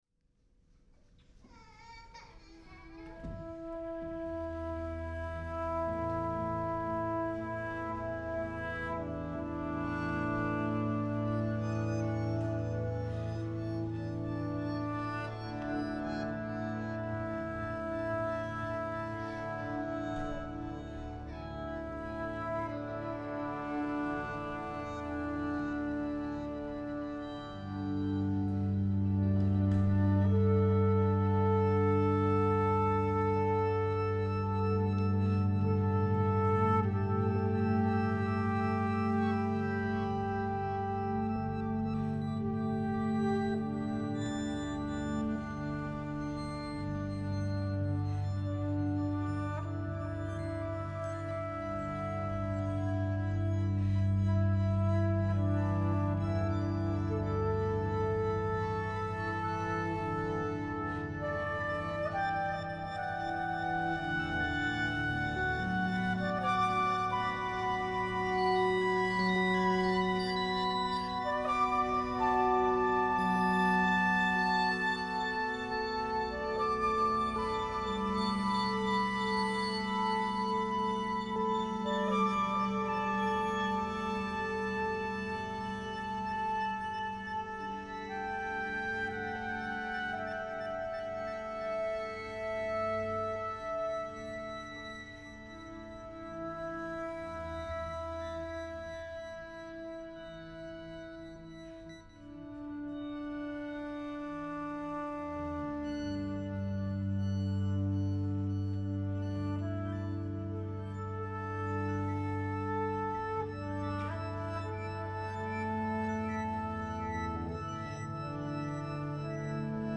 Flute, Live Electronics (2010)